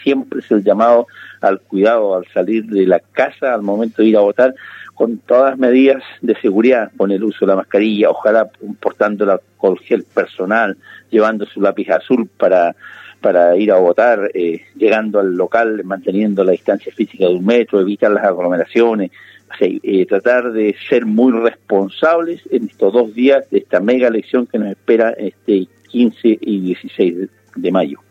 El gobernador de la provincia de Chiloé, Pedro Andrade, sostuvo que se han tomado todas las medidas sanitarias para el acto eleccionario de este fin de semana y llamó a la comunidad a ser responsables con el autocuidado en medio de las cifras de contagios por el covid-19.  En conversación con Primera Hora de Radio Sago la autoridad sostuvo que hay una preocupación evidente por la movilización de las personas y la situación sanitaria que se vive.